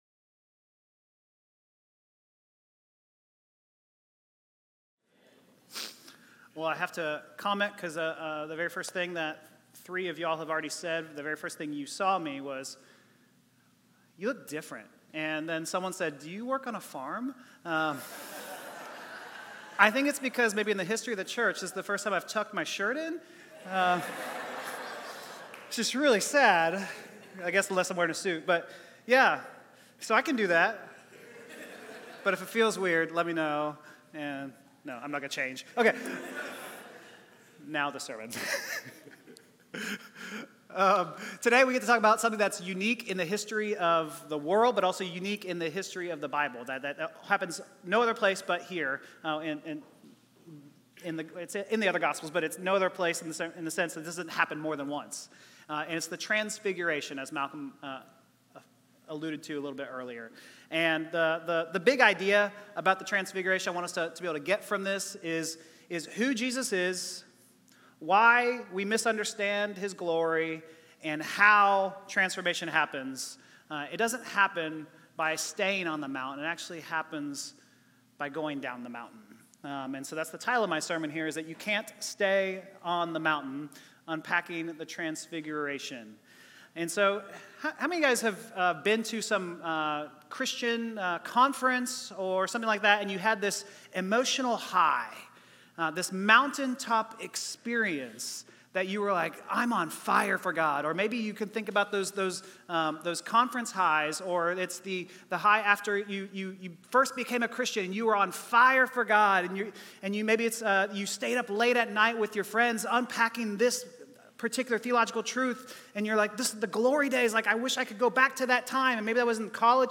9AM Service Feb 15th 2026